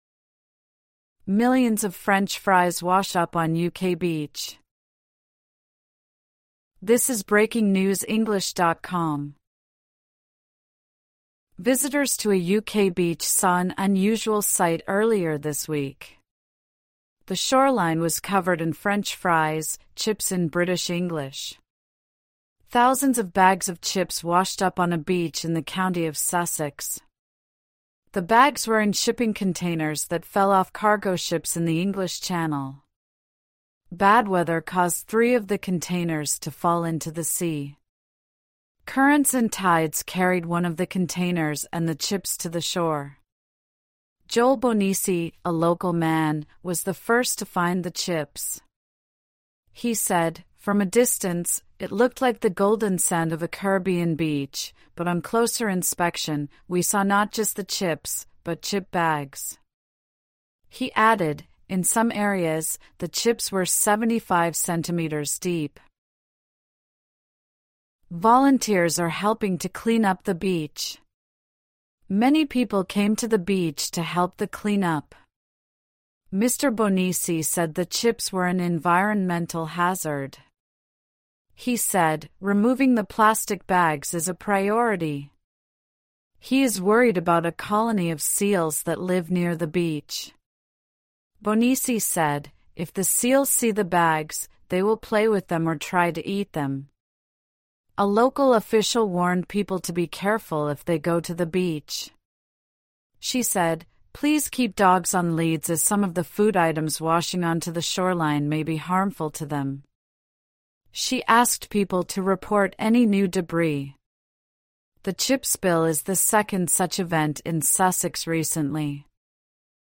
AUDIO (Normal)